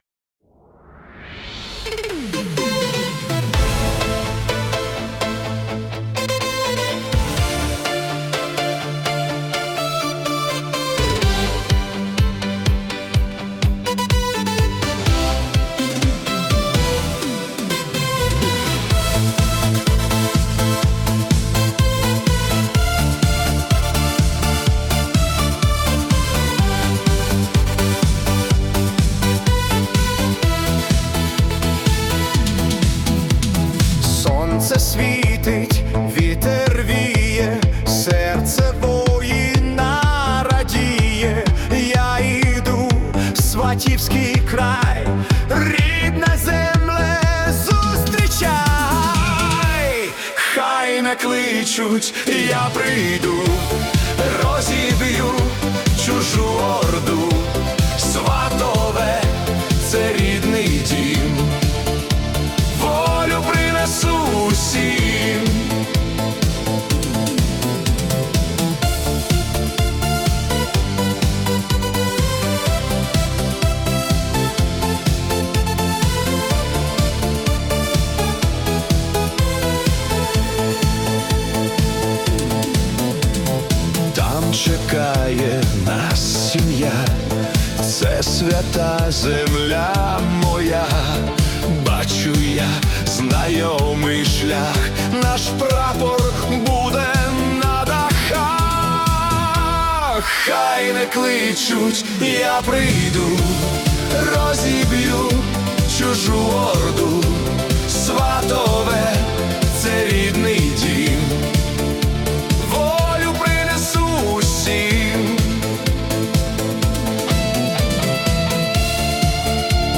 Euro Disco / Pop-Rock